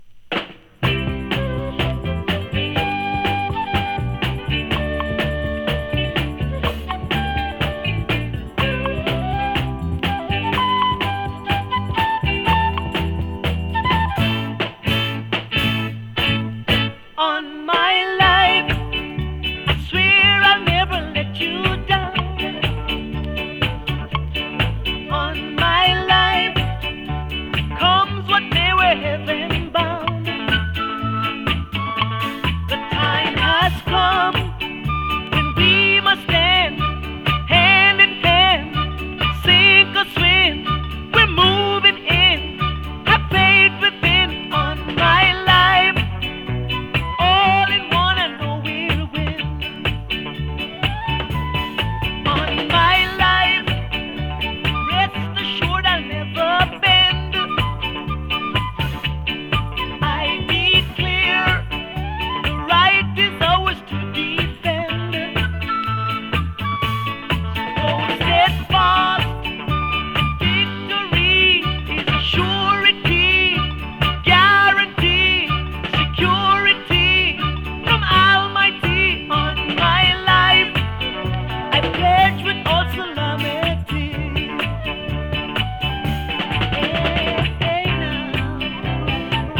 Bob Marleyに次いでアメリカで売れたレゲエ・シンガーによる、ジャマイカはダイナミック・スタジオ録音。
＊所々極軽いパチ・ノイズ。